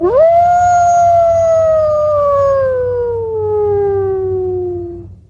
Wolf Howl Sound Effect Free Download
Wolf Howl